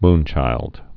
(mnchīld)